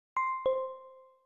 Didong.ogg